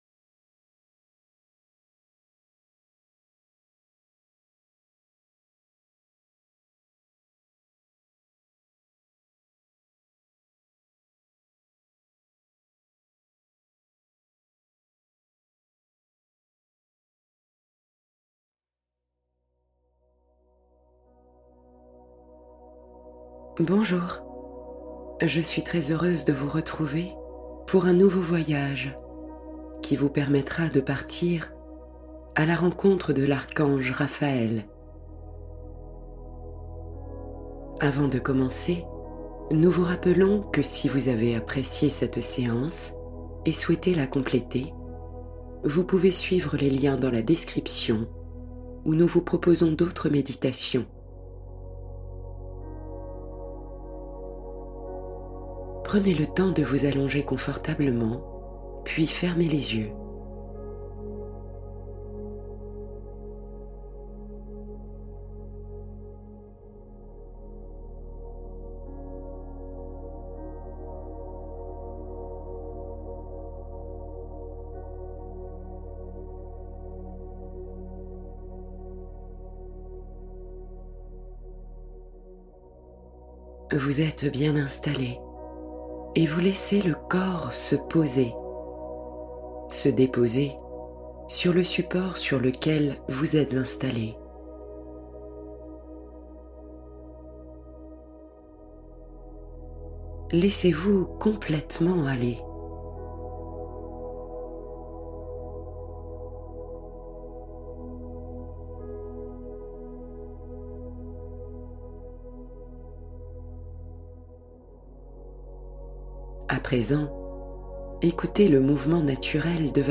Conte du soir — Voyage au clair de lune pour s’endormir paisiblement